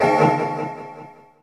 ECHO CLANG.wav